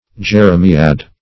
Jeremiad \Jer`e*mi"ad\, Jeremiade \Jer`e*mi"ade\, n. [From